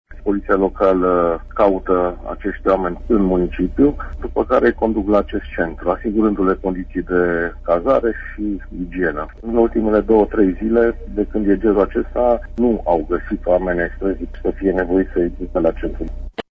La Sighișoara primăria colaborează cu un așezământ al Bisericii Ortodoxe unde sunt primite peste noapte 30 de persoane, explică primarul Sighișoarei, Ovidiu Mălăncrăvean: